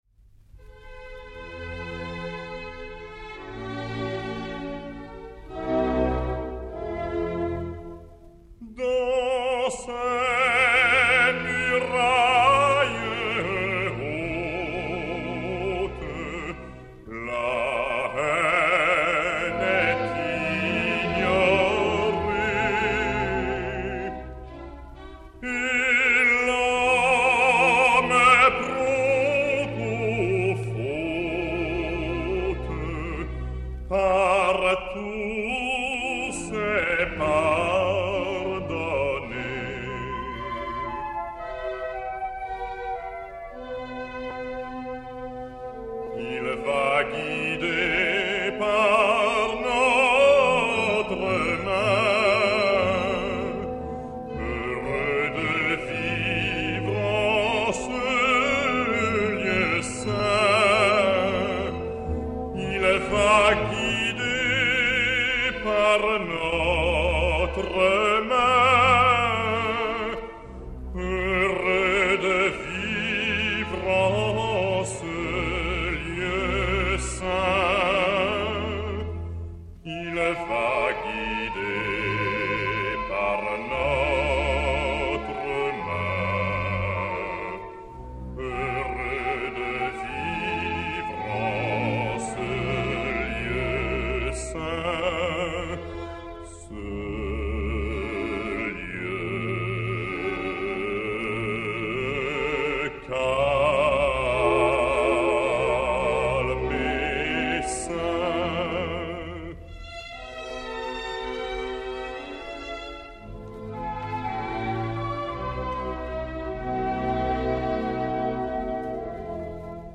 Xavier Depraz (Sarastro) et Orch de l'Ass. des Concerts Colonne dir Louis de Froment